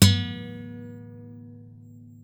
Segunda cuerda de una guitarra
cordófono
guitarra